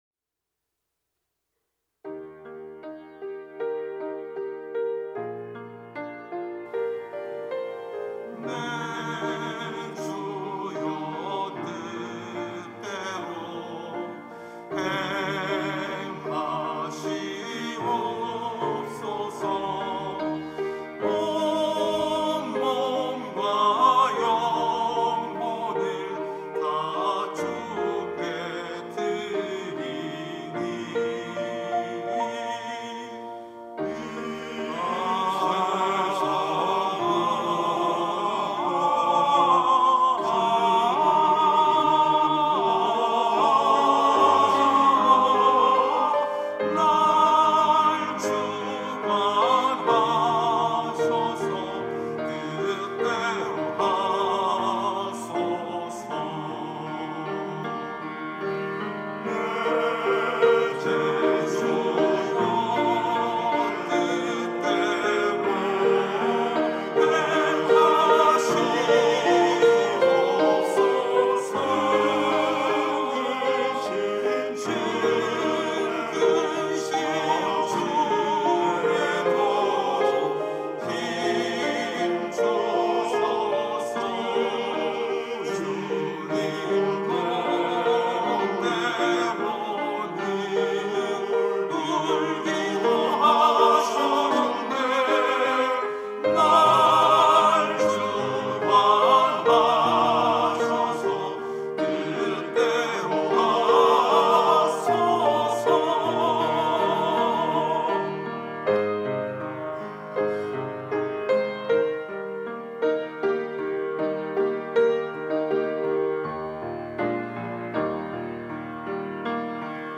찬양대